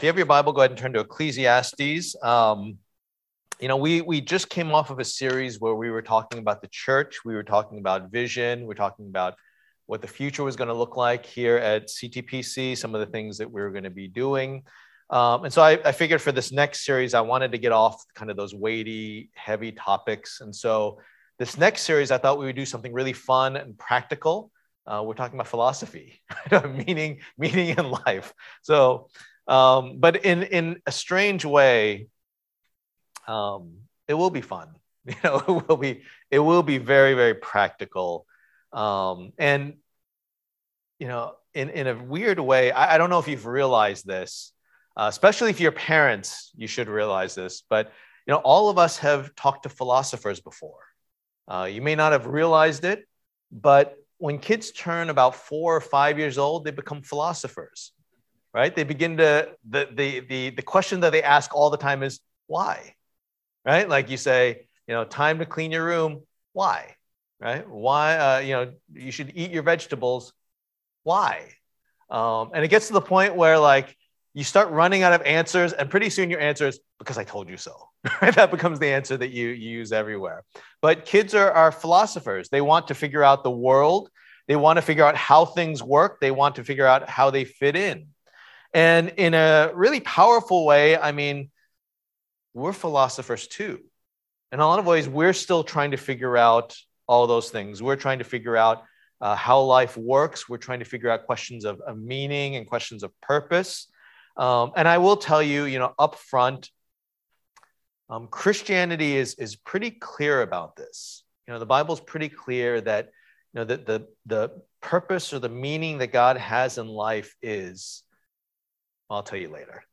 Passage: Ecclesiastes 1:1-18 Service Type: Lord's Day